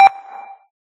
Electrocardiogram.ogg